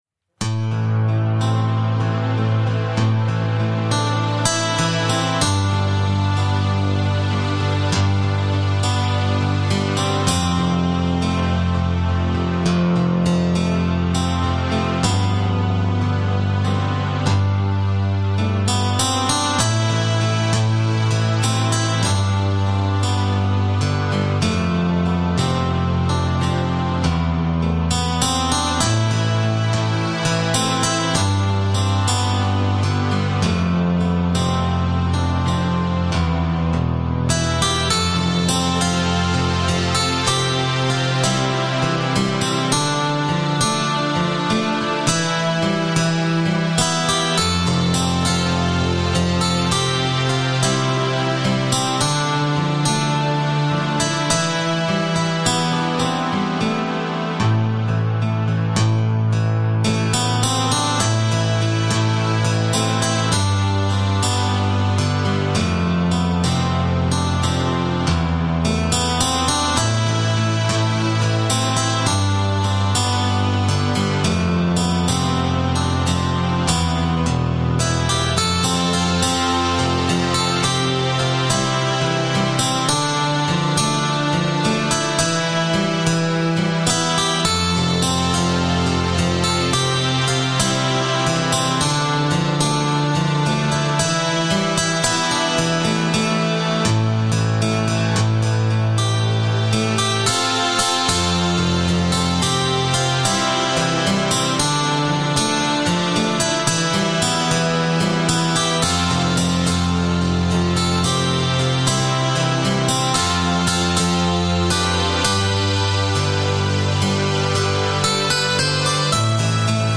2017 Wise Men Still Seek Jesus Preacher